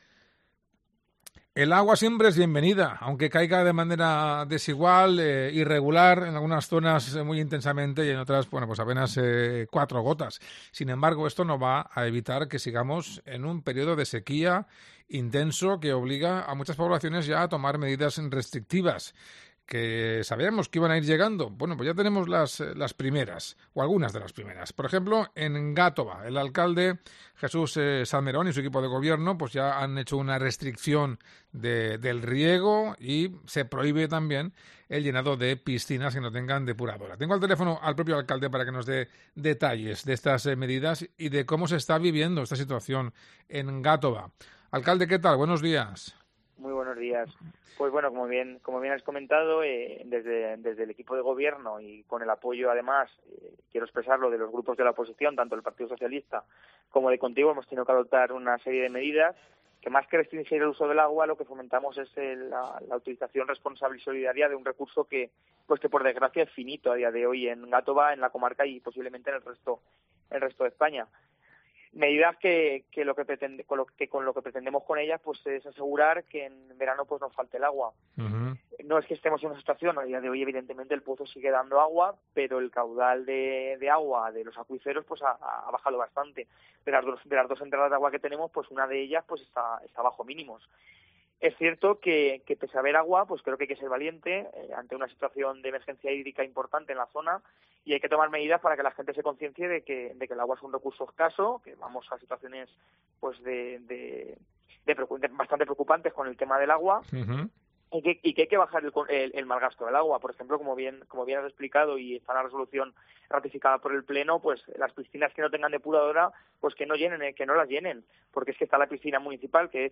Gátova establece medidas estrictas que entrarán en vigor el 15 de junio. Su alcalde, Jesús Salmerón, anuncia exenciones fiscales para quien haga un uso responsable del agua